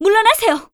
cleric_f_voc_sticker_09.wav